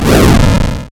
SE_chargeshot.wav